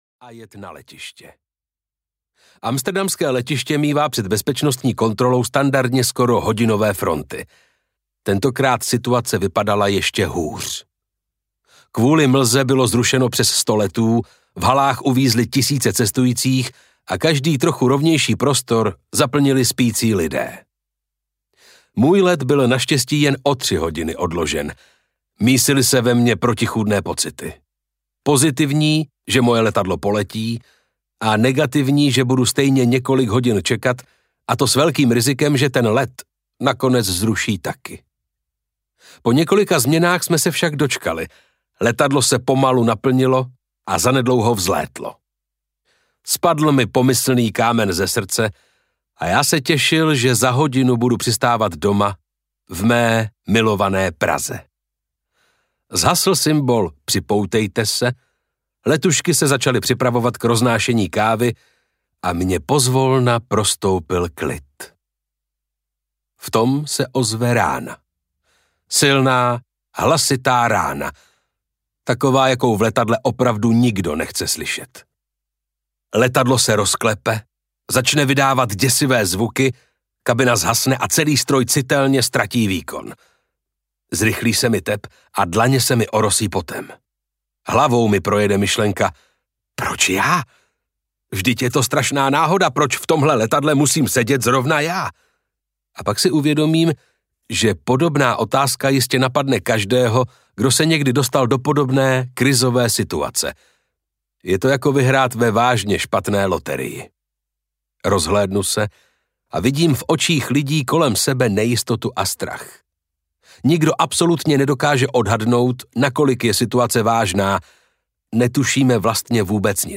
Od chaosu ke smyslu audiokniha
Ukázka z knihy
od-chaosu-ke-smyslu-audiokniha